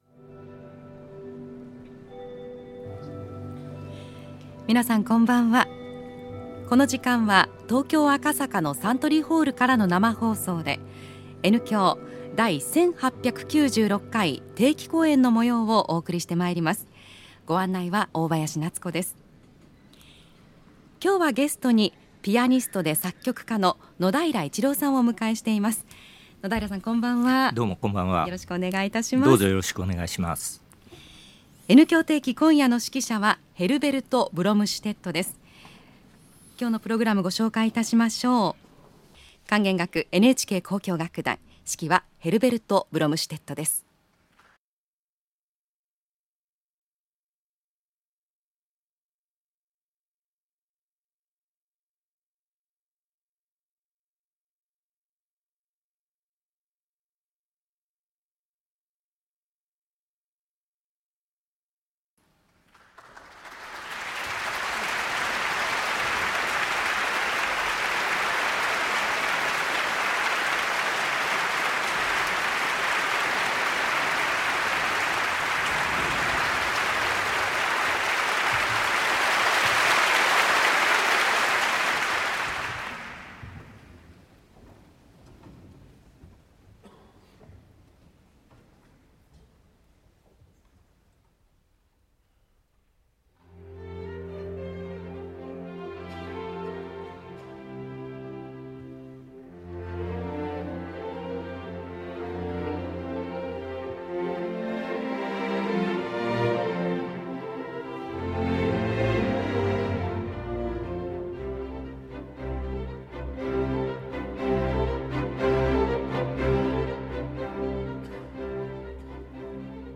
Herbert Blomstedt With The NHK Symphony In Music Of Beethoven And Stenhammar - 2018 - recorded at Suntory Hall, Tokyo - Past Daily Mid-Week Concert.
Herbert Blomstedt and The NHK Symphony in Concert – October 24, 2018 – Suntory Hall, Tokyo – NHK-FM –
The legendary Herbert Blomtedt leading the NHK Symphony in music of Beethoven and Stenhammer during this Subscription concert on October 24, 2018 and recorded live from the stage at Suntory Hall in Tokyo by NHK-FM.